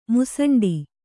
♪ musaṇḍi